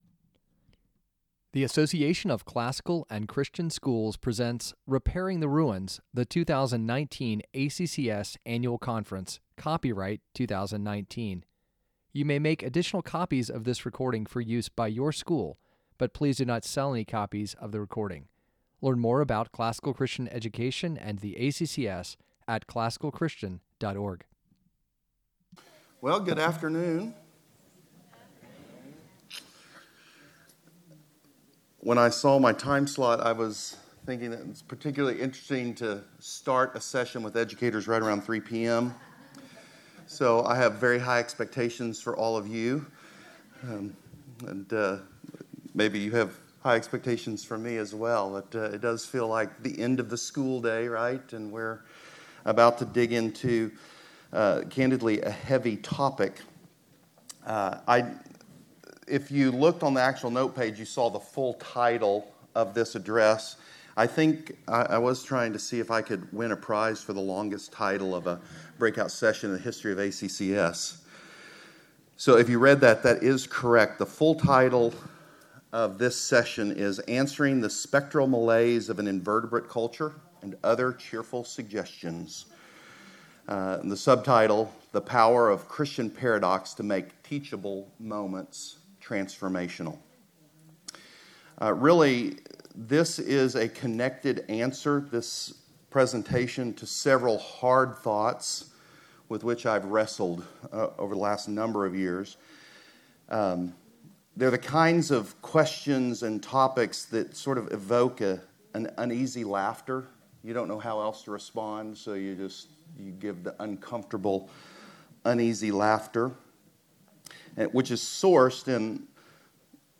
2019 Workshop Talk | 50:46 | All Grade Levels, Teacher & Classroom, Virtue, Character, Discipline